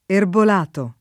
[ erbol # to ]